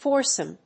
音節four・some 発音記号・読み方
/fˈɔɚsəm(米国英語), fˈɔːsəm(英国英語)/